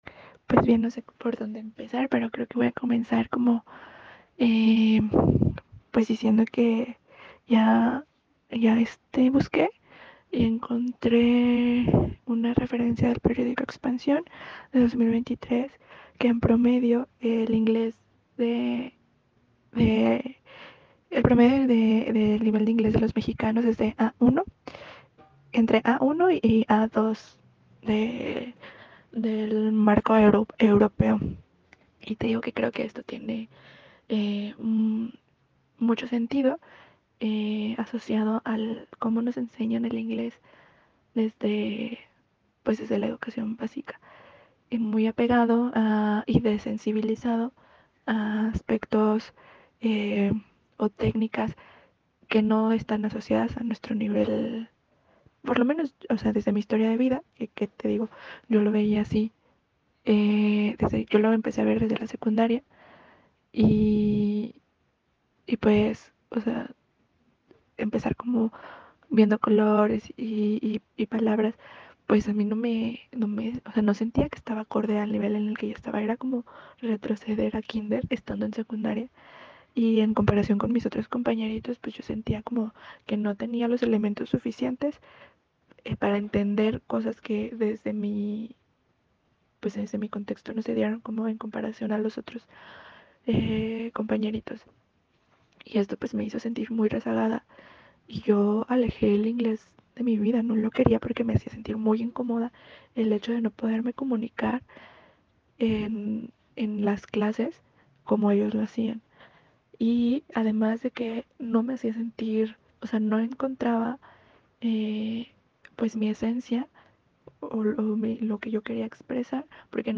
TESTIMONIOS
Dale play para escuchar este testimonio en audio de una de nuestras alumnas.